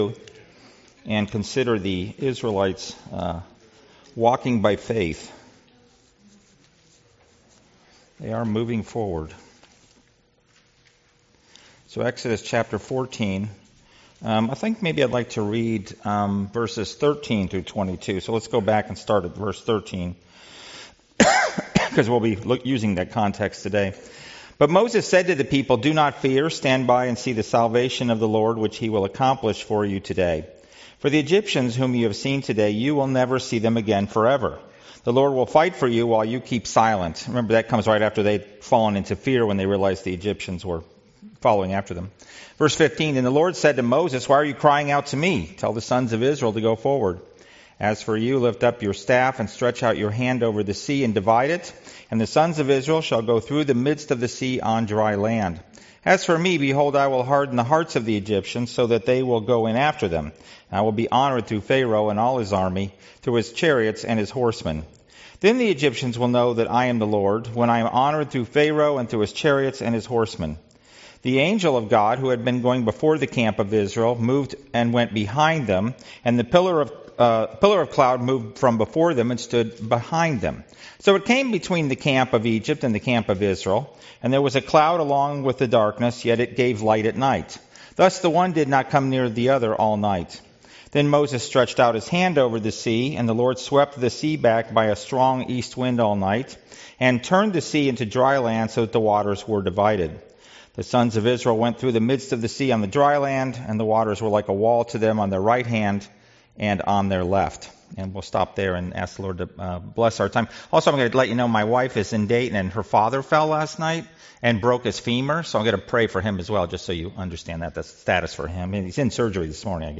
Passage: Exodus 14 Service Type: Sunday School « Sorrento